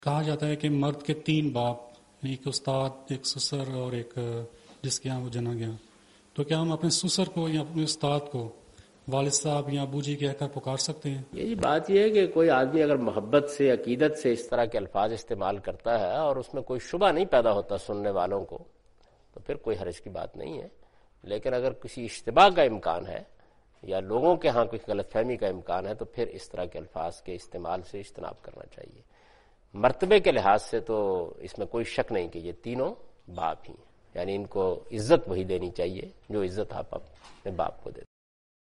Category: English Subtitled / Questions_Answers /
Javed Ahmad Ghamidi responds to the question' Is it permissible to call one’s teacher or father-in-law as ‘Father’?